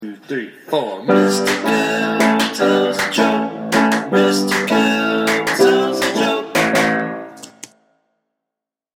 We’ve thrown in some David Letterman-inspired bits (complete with their own theme songs) like: